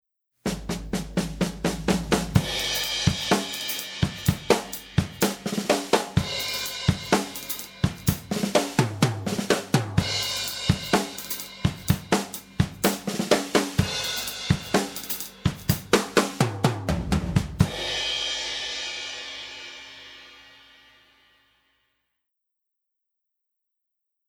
It’s also obvious that the compressor on the parallel bus is really crushing the signal.
Notice that there’s almost 20db of gain reduction.
This is what the compressed signal sounds like by itself: